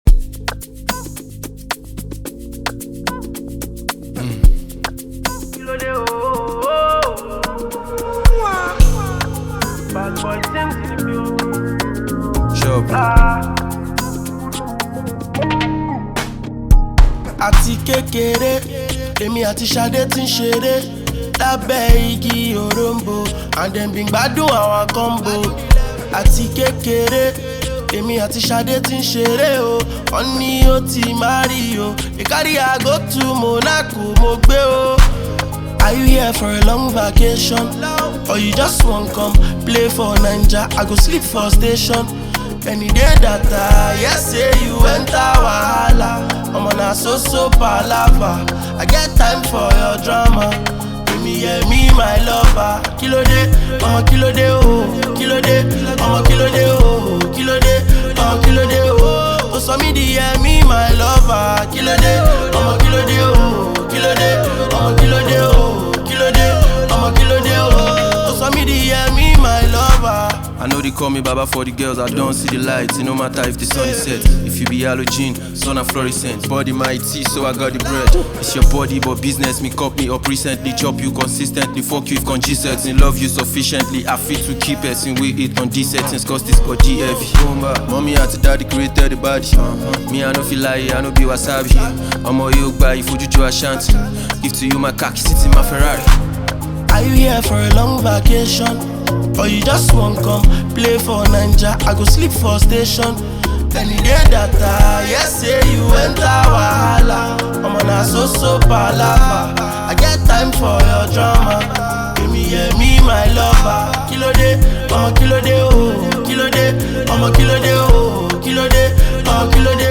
blends Afrobeat with contemporary sounds
With its high energy tempo and catchy sounds